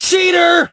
hotshot_hurt_06.ogg